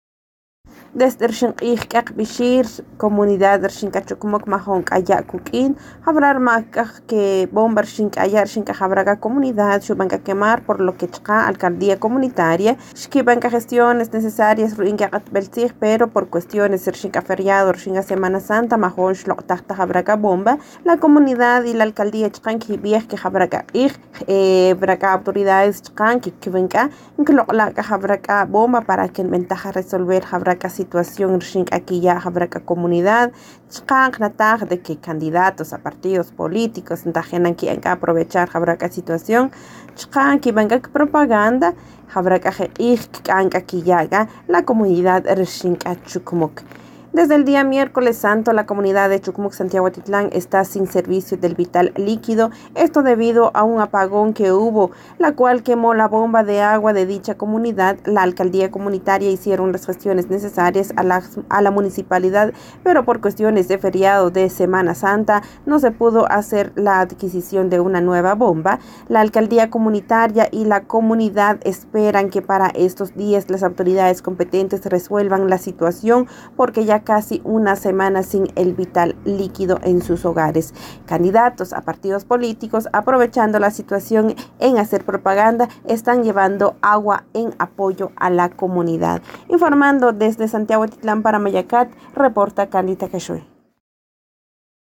Informa en idiomas Tz’utujil y español